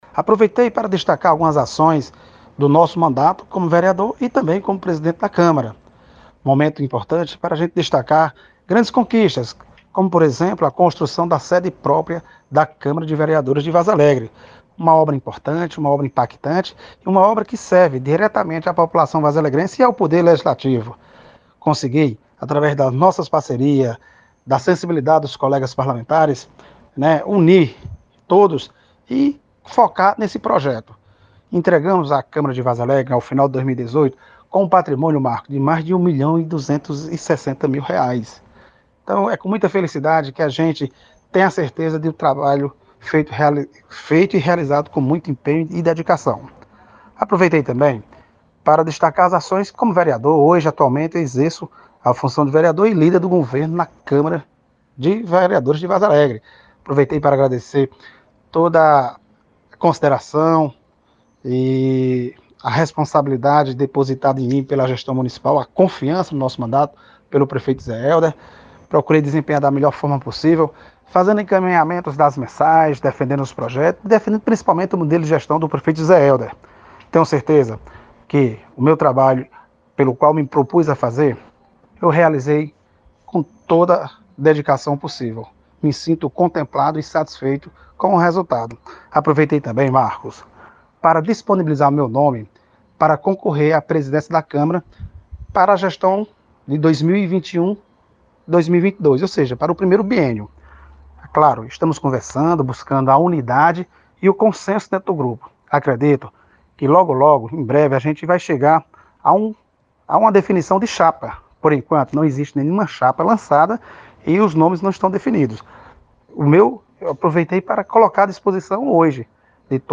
Em discurso na tribuna da Câmara de Vereadores, nesta quarta-feira, 09, o vereador Alan Salviano, MDB, lançou o seu nome para concorrera à presidência do poder legislativo para o biênio 2021\2022.
Vereador-Alan-Salviano.mp3